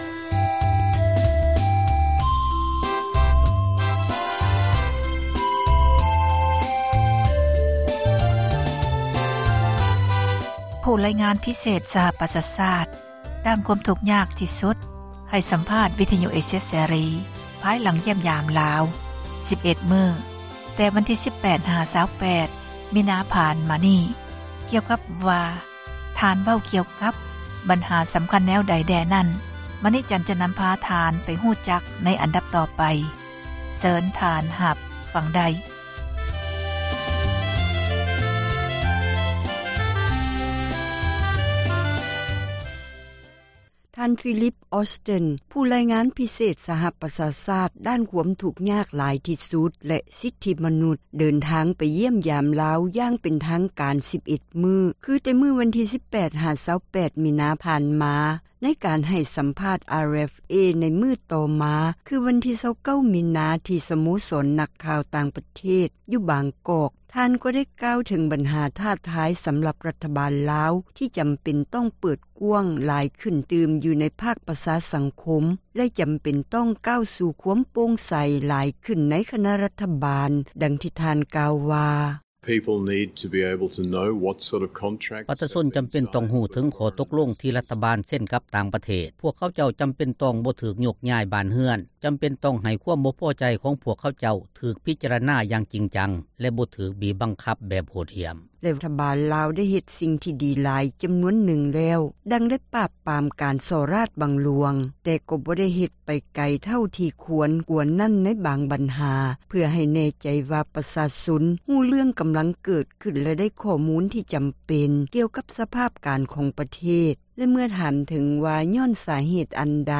ຜູ້ຣາຍງານພິເສດ ສະຫະປະຊາຊາດ ດ້ານຄວາມທຸກຍາກ ທີ່ສຸດ ແລະ ສິດທິມະນຸດ ໃຫ້ ສັມພາດ RFA ພາຍຫລັງ ຢ້ຽມຢາມ ລາວ ແຕ່ ວັນທີ 18 ຫາ 28 ມິນາ.